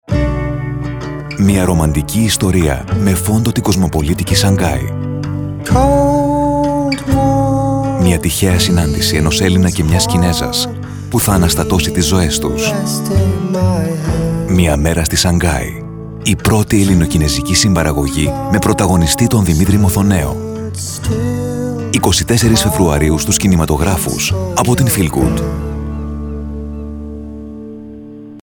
Fast, polished delivery in native Greek or English.
Movie Trailers
BassDeepLow
WarmCharming